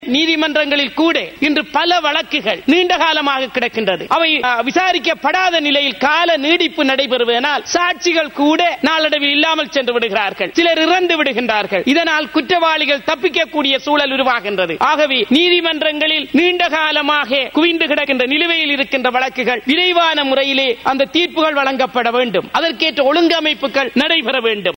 மேல் நீதிமன்ற மற்றும் உயர் நீதிமன்ற நீதிபதிகளின் வேதன உயர்வு தொடர்பில் நீதி அமைச்சர் விஜயதாஸ ராஜபக்ஷவினால் கொண்டுவரப்பட்ட பிரேரணை தொடர்பான வாத விவாதங்கள் இன்று நாடாளுமன்றில் இடம்பெற்றது.